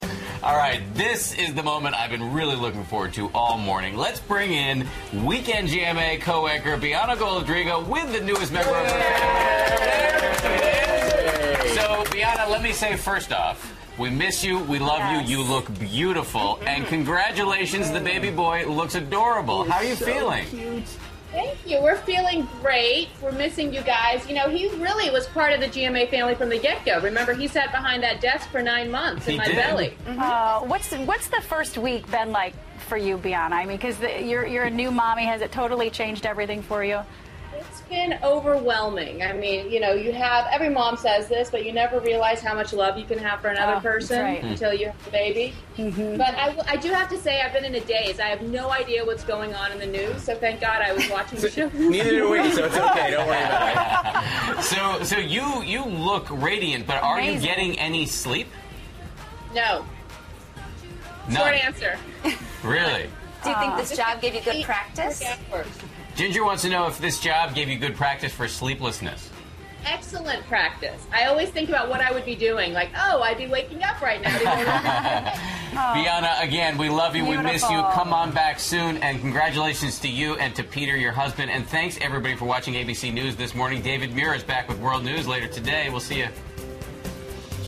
访谈录 2012-04-12&04-14 听女主播讲做妈妈的幸福 听力文件下载—在线英语听力室